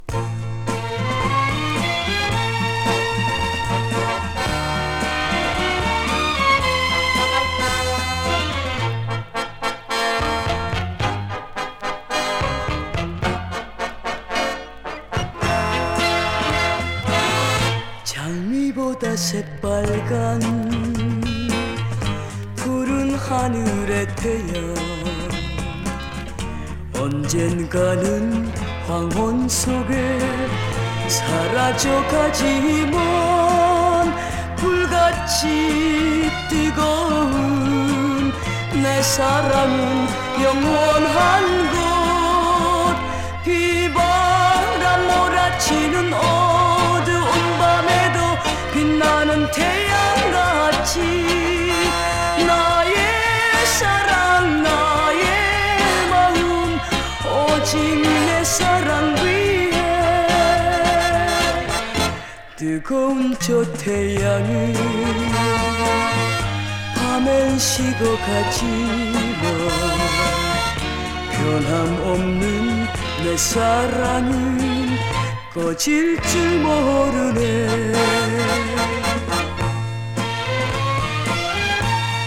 グルーヴィーな